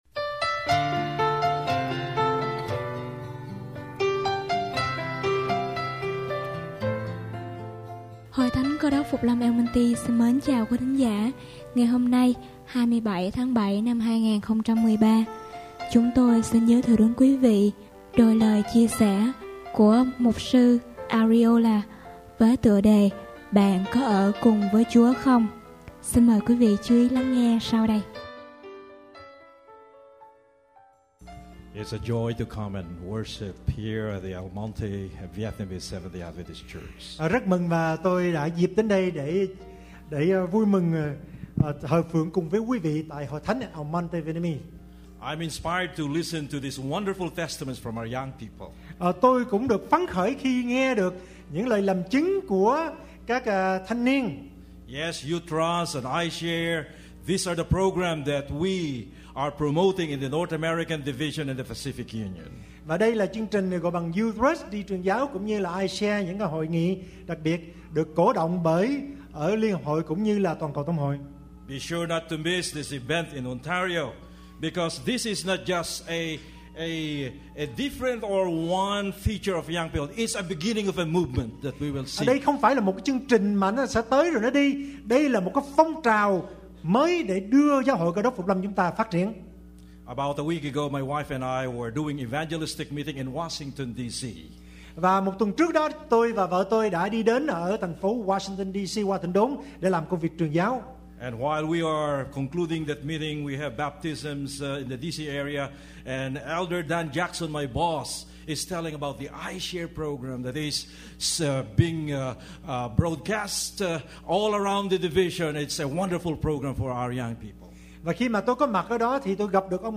Sermon / Bài Giảng